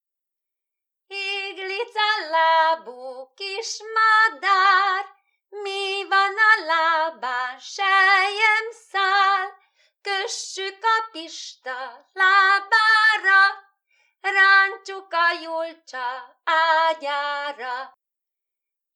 TípusI. Népi játékok / 13. Párválasztó körjátékok
TelepülésKarva [Kravany nad Dunajom]